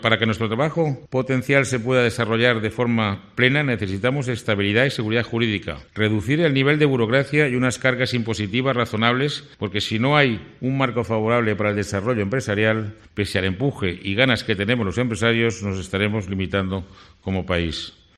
En estos términos se han pronunciado durante el acto de presentación de la segunda edición del Barómetro del Empresario, impulsado por AVE, que determina qué aportan los empresarios a la sociedad y cómo les valora la ciudadanía.